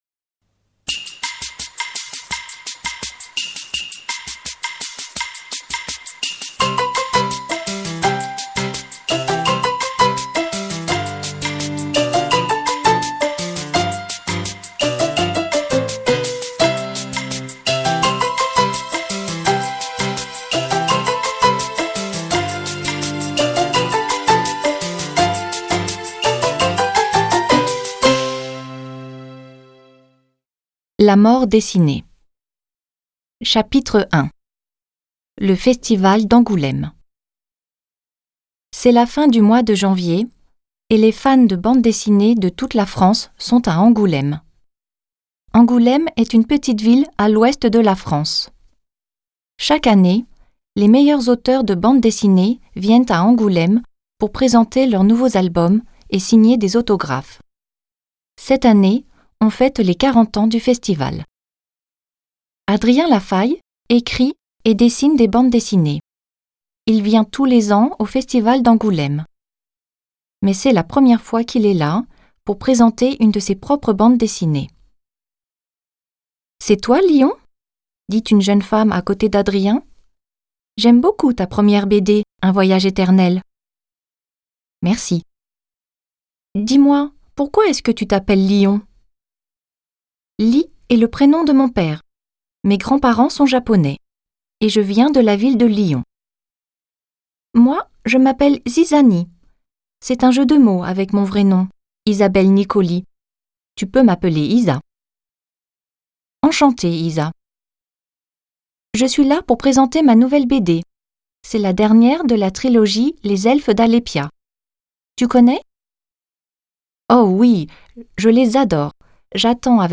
La mort dessinee Hörbuch Folgende Hördialoge stehen zur Verfügung: